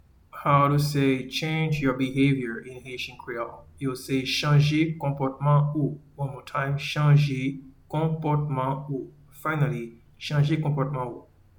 Pronunciation:
Change-your-behavior-in-Haitian-Creole-Chanje-konpotman-ou.mp3